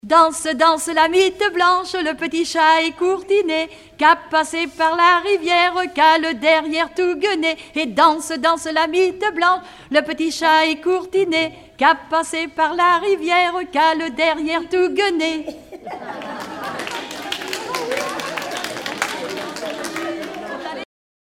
Couplets à danser
Regroupement de chanteurs du canton
Pièce musicale inédite